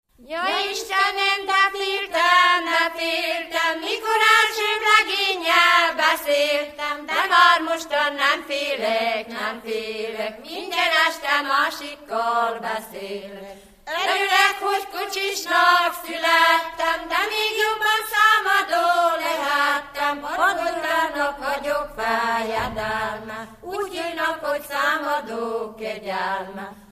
Alföld - Bács-Bodrog vm. - Dávod
Előadó: Asszonyok, ének
Stílus: 4. Sirató stílusú dallamok
Kadencia: 6 (5) 2 1